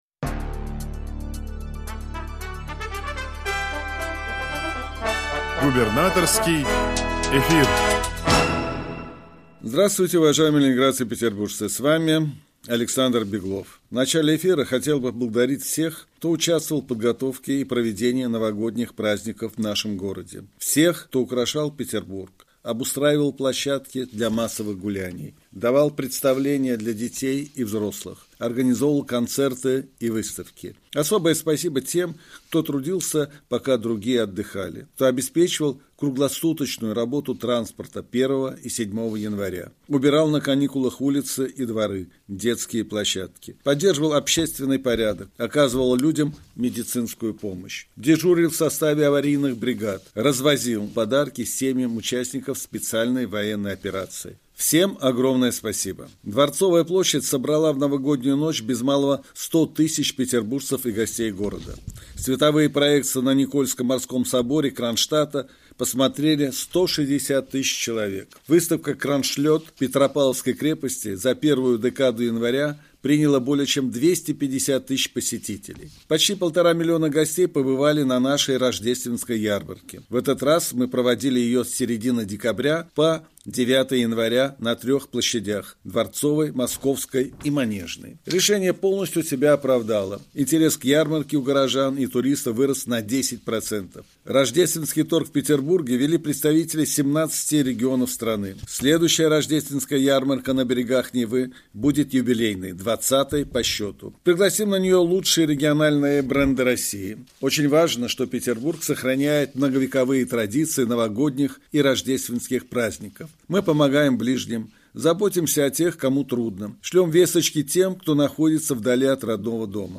Радиообращение – 13 января 2025 года